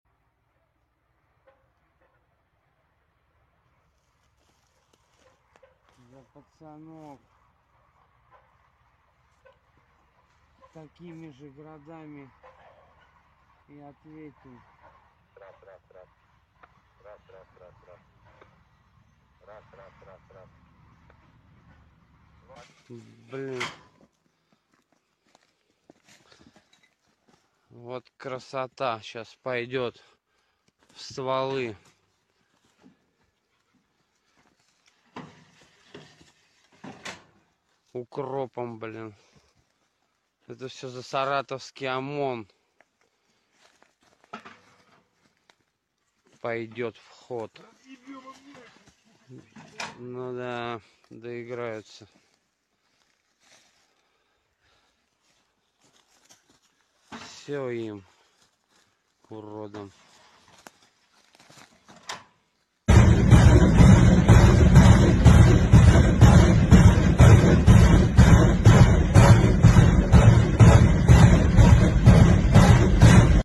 Dari kejauhan, tabung peluncur melepaskan hujan api 122mm, menghantam posisi pasukan Ukraina dan meratakan pertahanan mereka dalam hitungan detik. ⚔ Satu salvo Grad = puluhan ledakan mematikan, badai baja yang tak bisa ditahan!